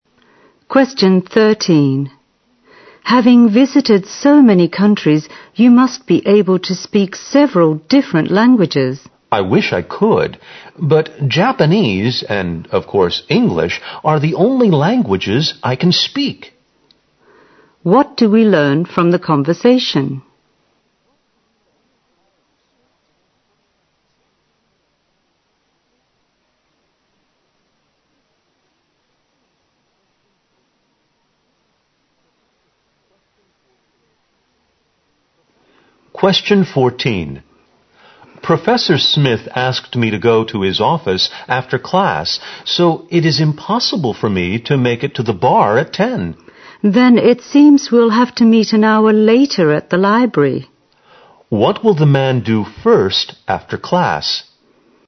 在线英语听力室061的听力文件下载,英语四级听力-短对话-在线英语听力室